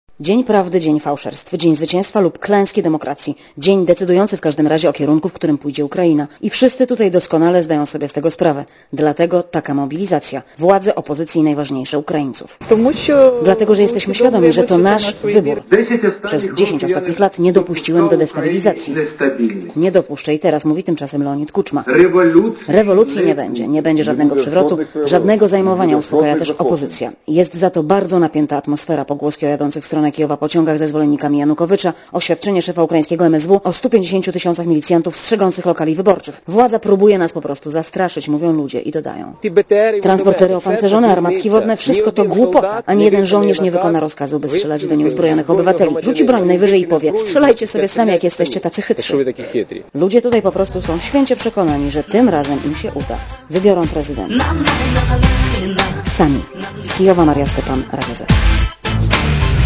ukraina-ii_tura.mp3